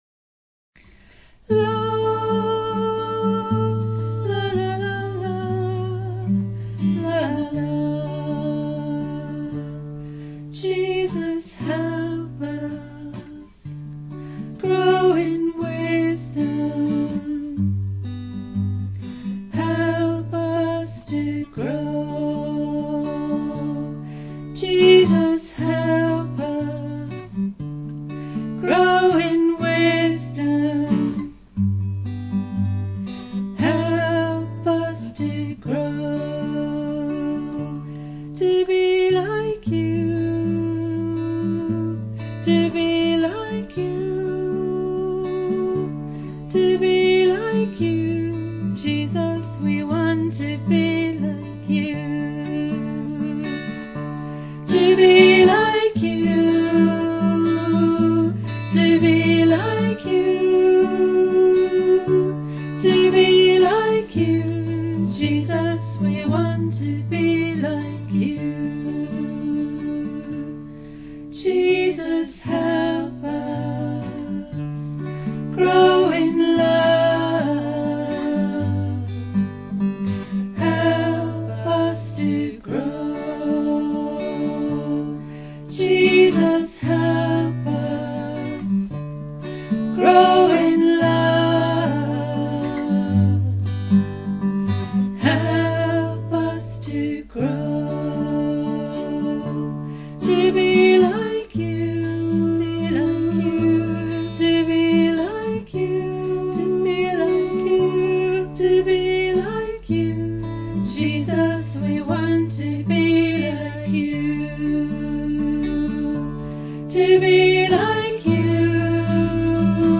Shorter, more meditative, songs